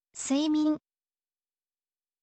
suimin